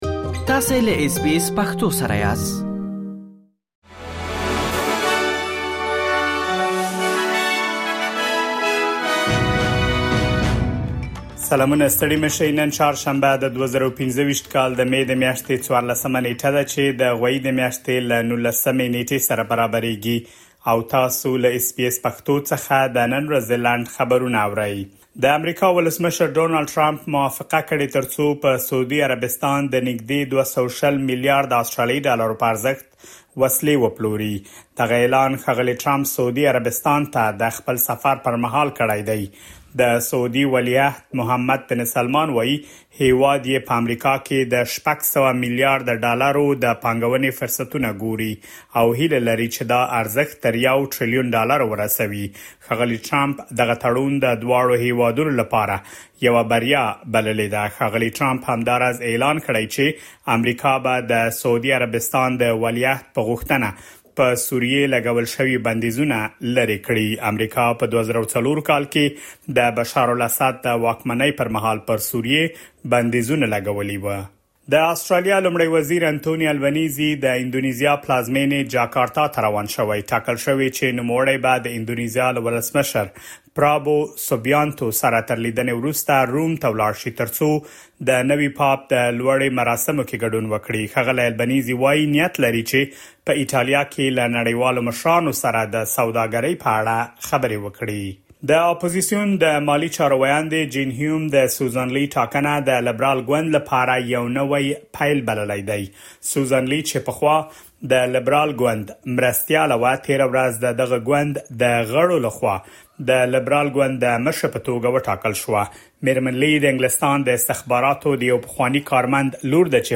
د اس بي اس پښتو د نن ورځې لنډ خبرونه | ۱۴ مې ۲۰۲۵
د اس بي اس پښتو د نن ورځې لنډ خبرونه دلته واورئ.